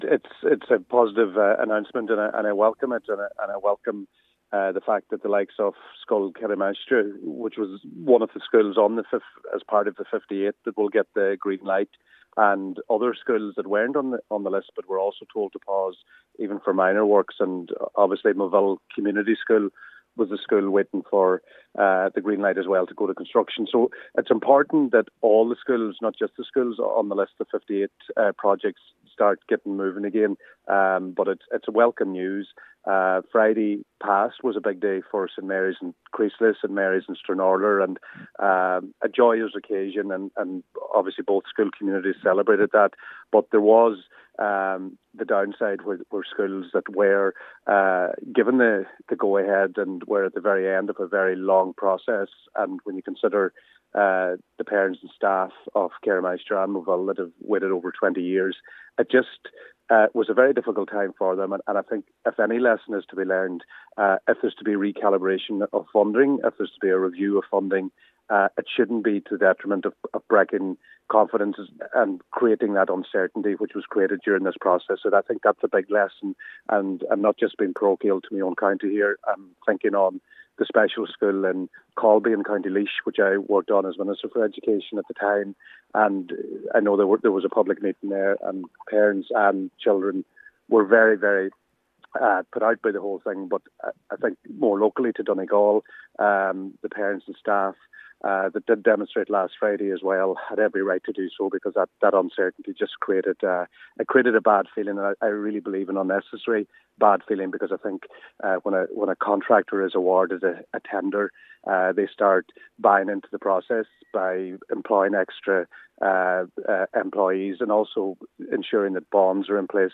Former Education Minister & Donegal Deputy Joe McHugh says lessons must be learned on behalf of the government: